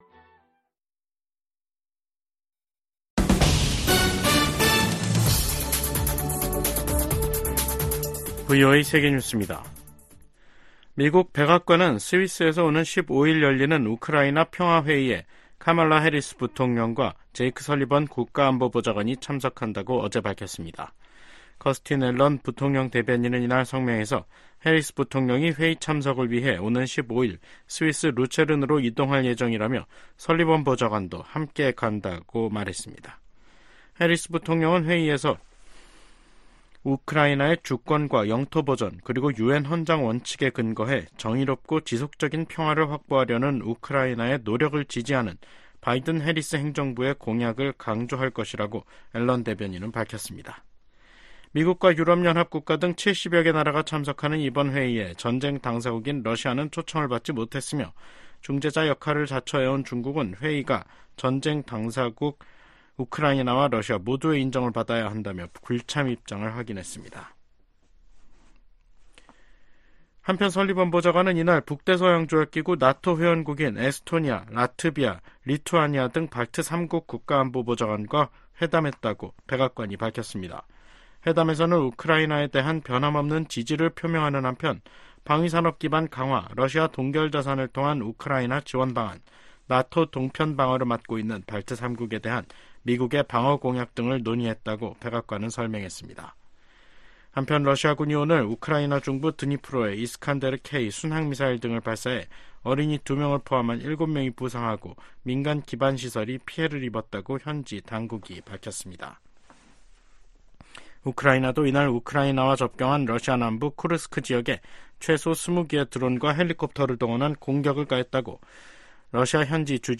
VOA 한국어 간판 뉴스 프로그램 '뉴스 투데이', 2024년 6월 4일 2부 방송입니다. 한국 정부는 9.19 군사합의 효력을 전면 정지시킴으로써 대북 확성기 사용과 함께 군사분계선(MDL)일대 군사훈련 재개가 가능하도록 했습니다. 미국의 북한 전문가들은 군사합의 효력 정지로 한국이 대비 태세를 강화할 수 있게 됐으나, 남북 간 군사적 충돌 위험성 또한 높아졌다고 진단했습니다.